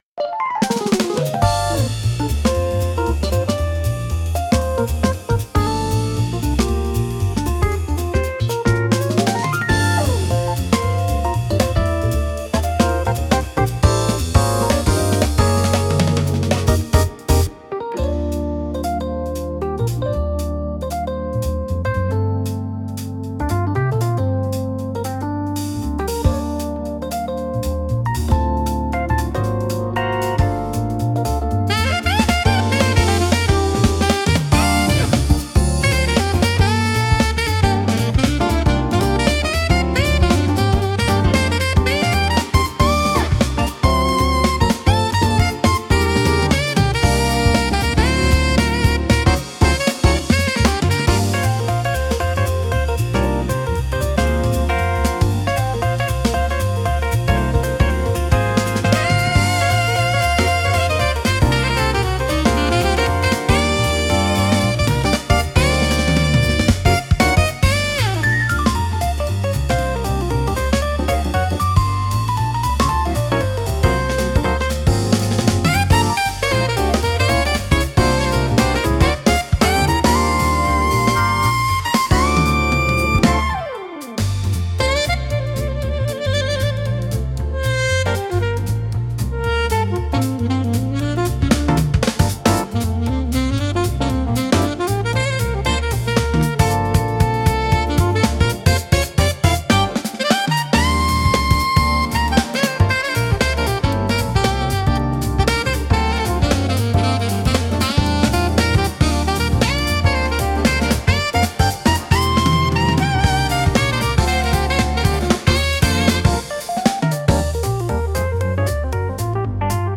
落ち着いた空気感を作り出しつつも、聴き疲れしにくい快適さがあり、ゆったりとした時間を楽しみたいシーンで多く活用されます。